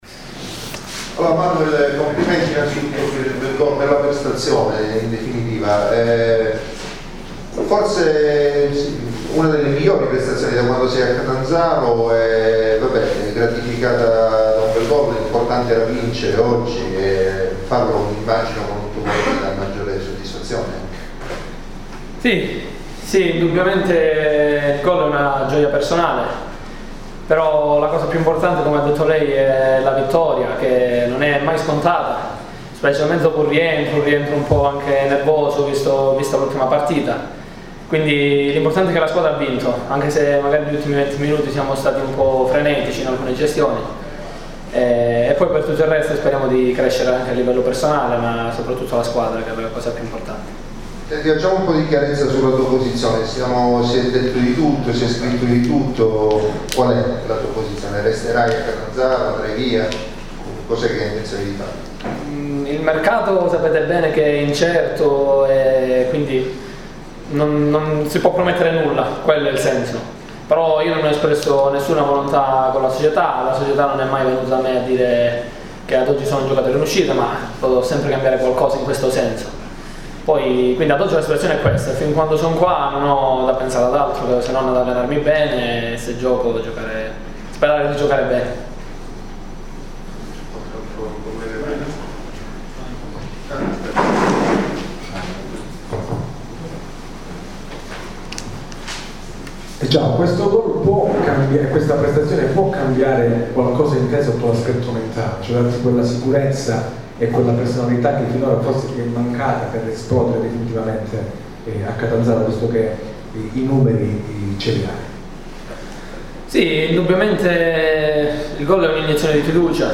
Ecco le interviste audio a fine partita: